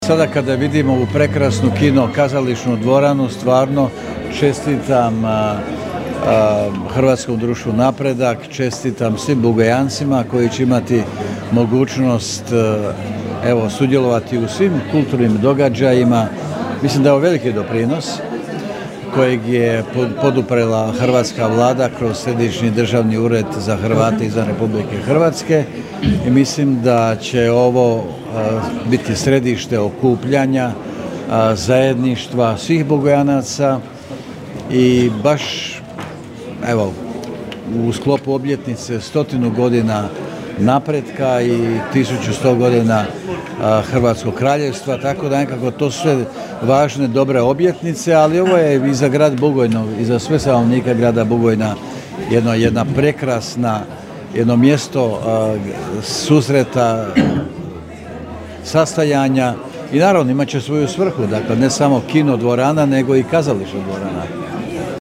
Obnovljeno kino-kazališnu dvoranu otvorio je Gordan Grlić Radman, ministar vanjskih i europskih poslova Republike Hrvatske koji je svojim podrijetlom vezan za Bugojno.
Gordan-Grlić-Radman-Ministar-vanjskih-poslova-i-EU-integracija.mp3